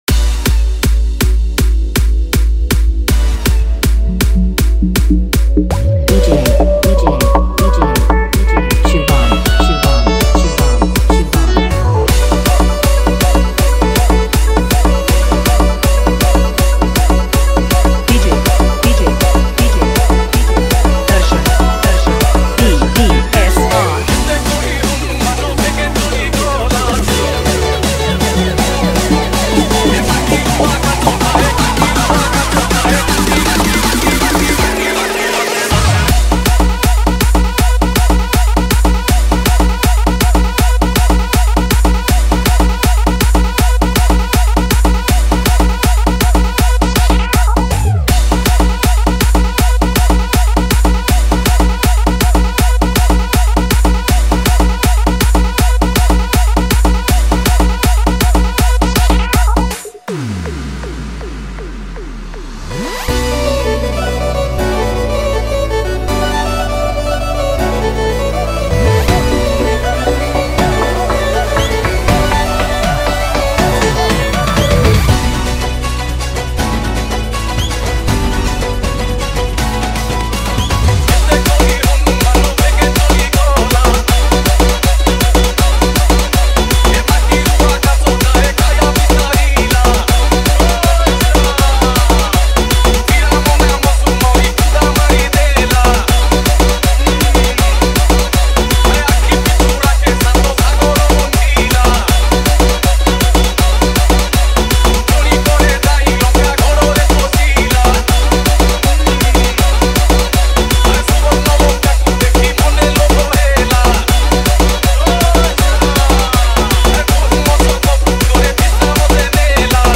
Bhajan Dj Song Collection 2025